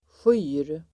Uttal: [sjy:r]